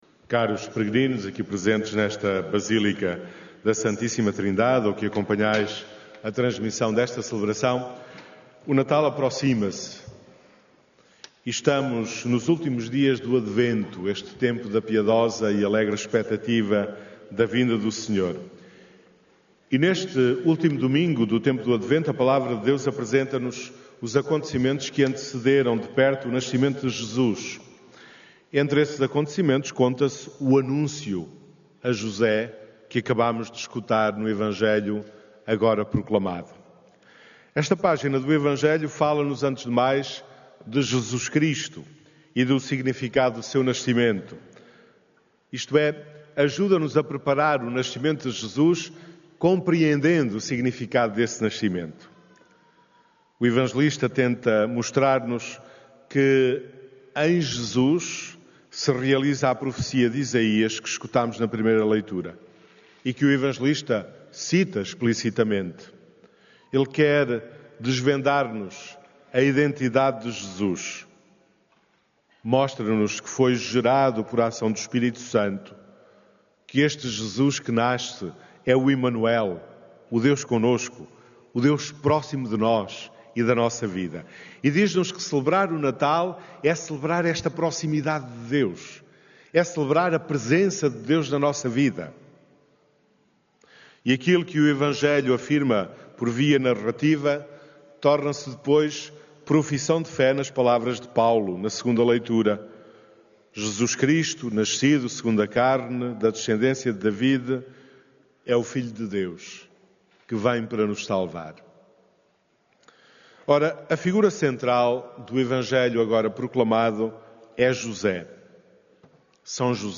Neste último Domingo do Advento, os peregrinos que participaram na missa das 11h00, na Basílica da Santíssima Trindade foram desafiados a viver este Natal no compromisso de uma entrega total a Deus, na disponibilidade interior e no sim incondicional à vontade divina.
Áudio da homilia